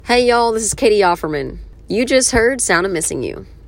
LINER